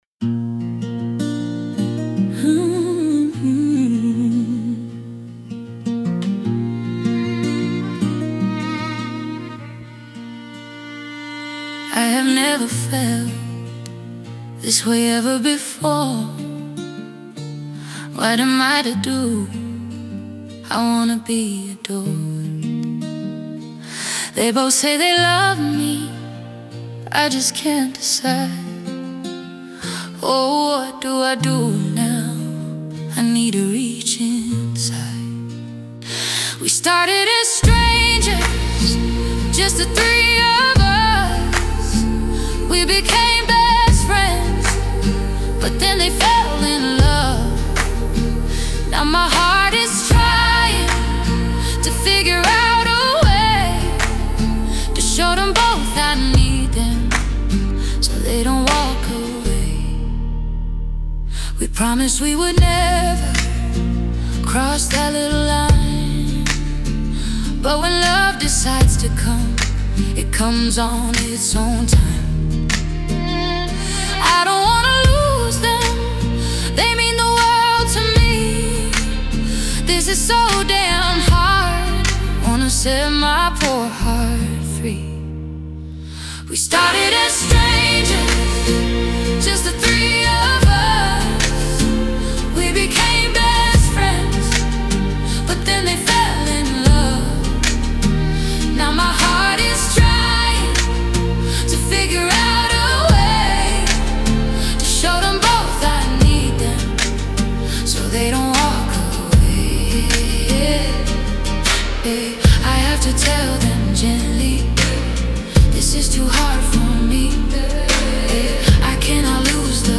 emotional R&B song